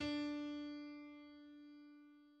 D: